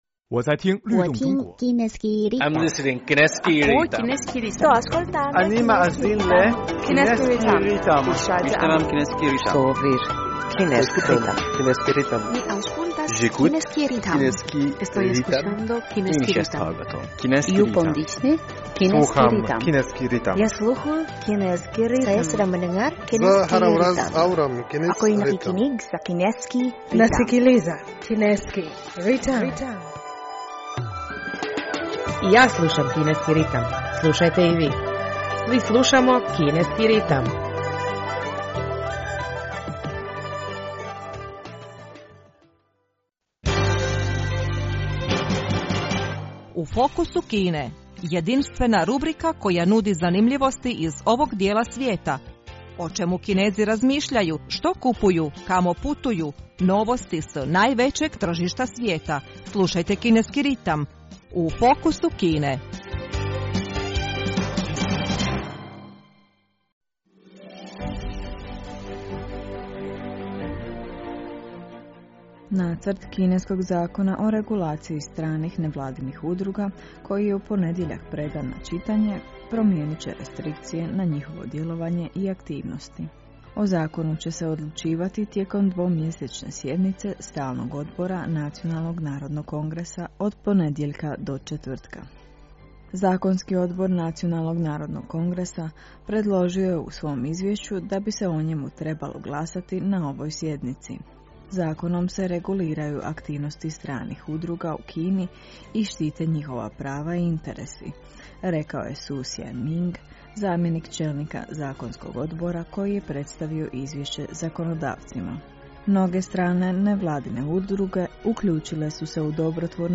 Slušate program na hrvatskom jeziku Kineskog radio Internacionala!